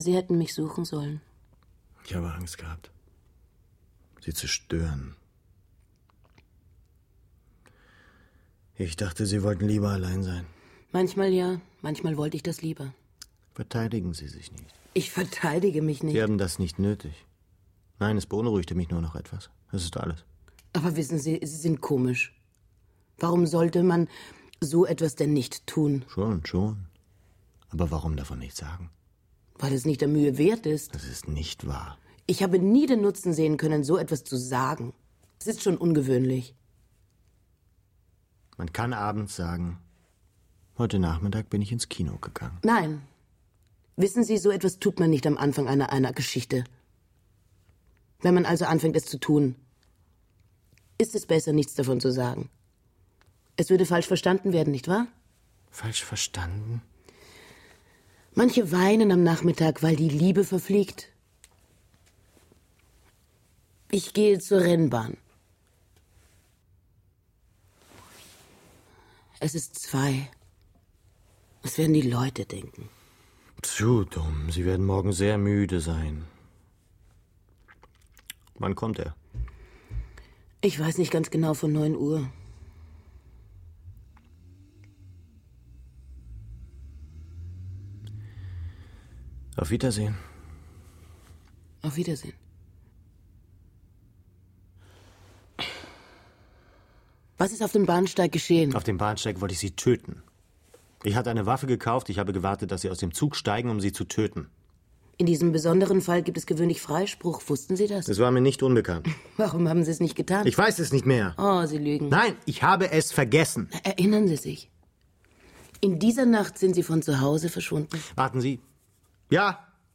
Radio | Hörspiel
Die Komposition von Carsten Nicolai grundiert daher nur und gleitet hin und her zwischen dunklen und hellen Tönen, quasi ein akustisches Ying und Yang. Über diesem feinen musikalischen Gewebe kann der Dialog sich entfalten.